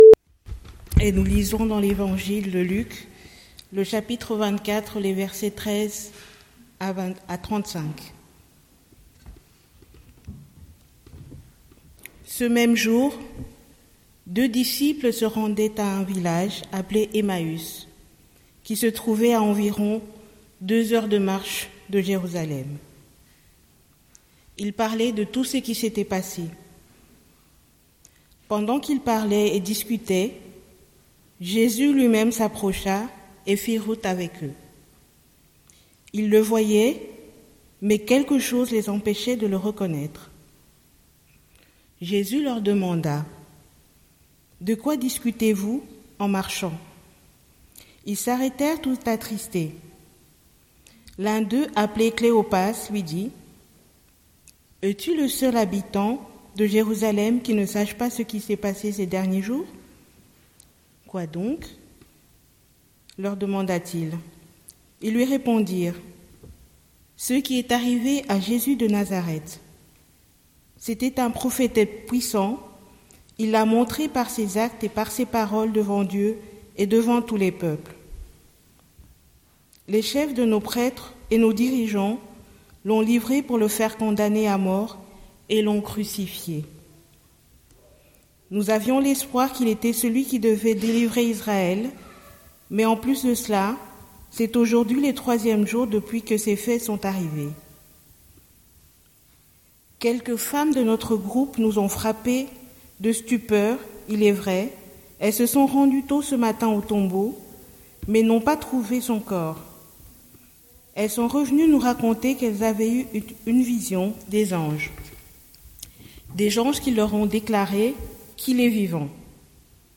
Prédication du 19/04/2026